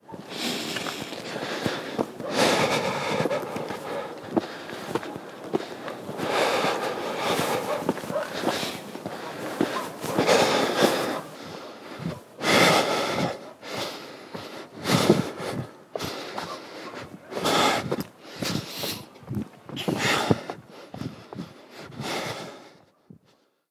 Respiración de un hombre haciendo ejercicio
Sonidos: Acciones humanas
Sonidos: Voz humana
Sonidos: Deportes